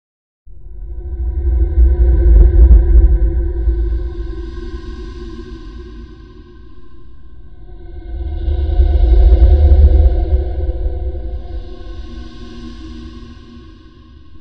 monster02.mp3